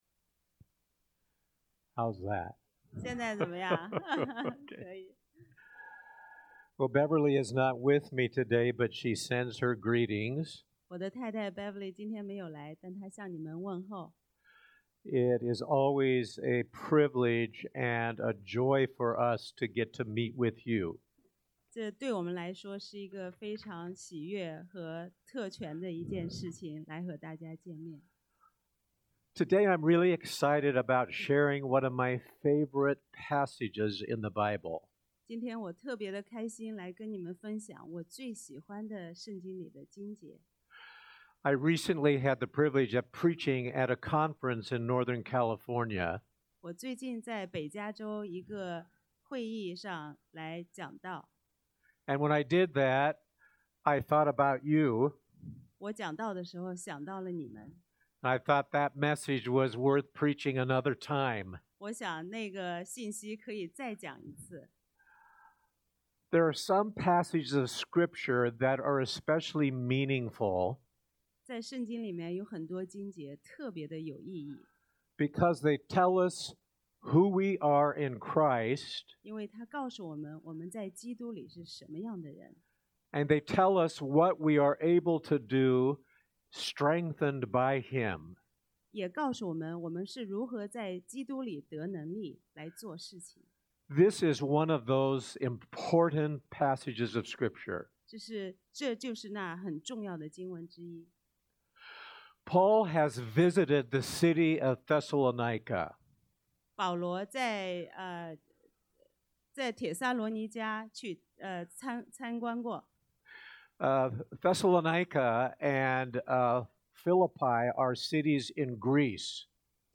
帖前 2 Thessalonians 2:1-13 Service Type: Sunday AM 1.